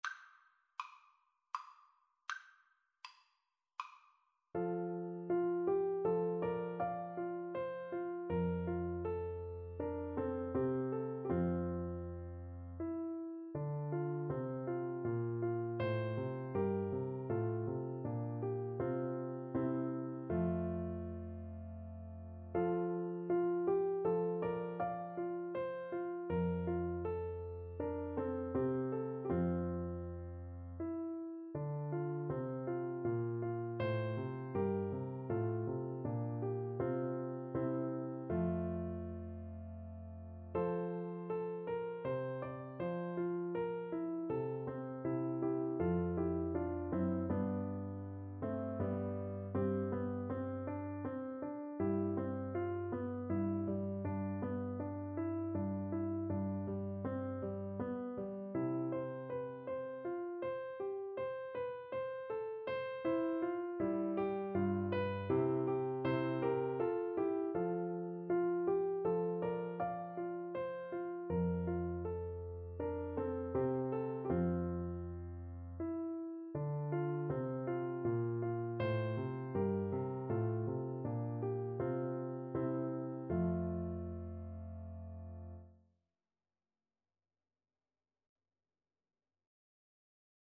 Play (or use space bar on your keyboard) Pause Music Playalong - Piano Accompaniment Playalong Band Accompaniment not yet available reset tempo print settings full screen
3/4 (View more 3/4 Music)
F major (Sounding Pitch) (View more F major Music for Recorder )
Andante
Classical (View more Classical Recorder Music)